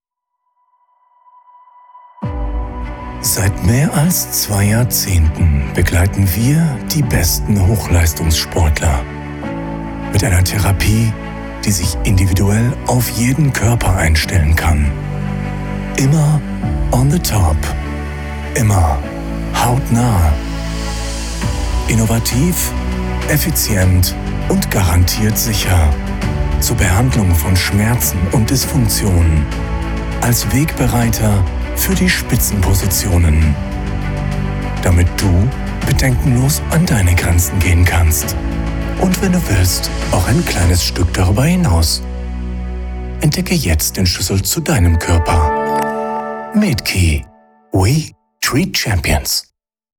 Comercial, Profundo, Llamativo, Seguro, Cálida
Corporativo